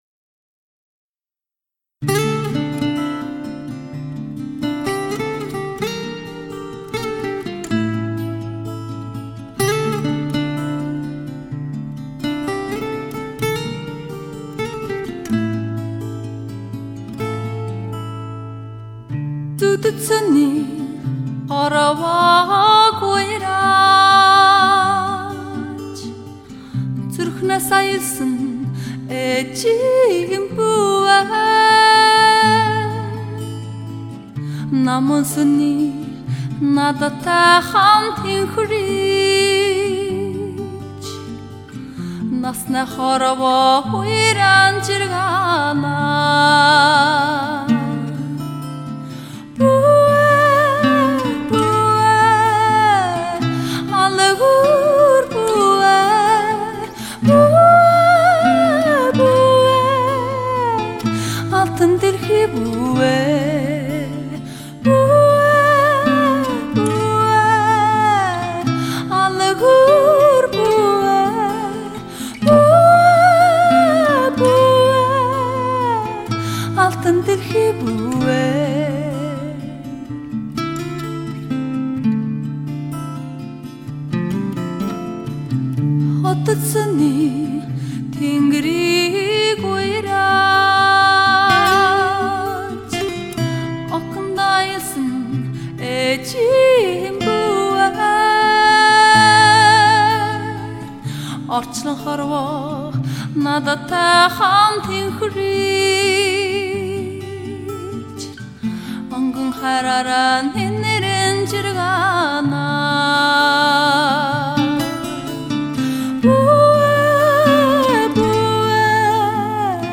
离苍天最近的声音，天人合一的绝妙吟唱。
听蒙古心灵深处的倾诉，感受马背民族的心声，有史以来最动听的蒙古女声唱片。